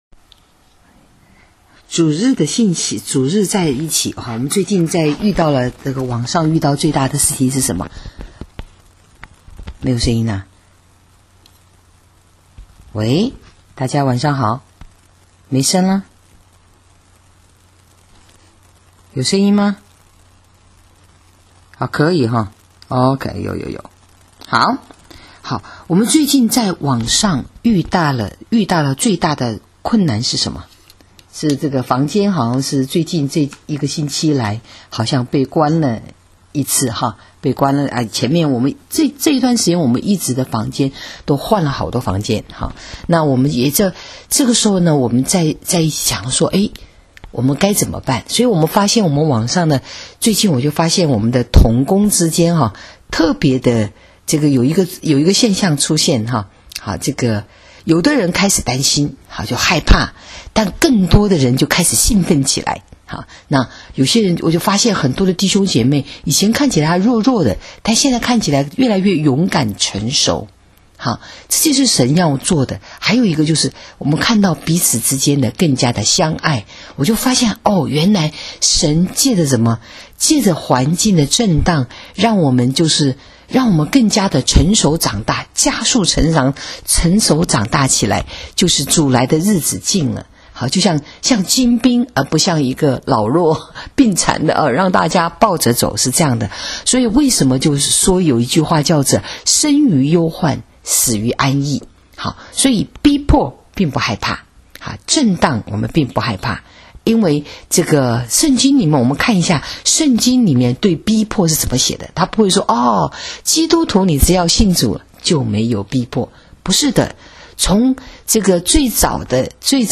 【主日信息】为义受逼迫的人有福了 （1-12-20）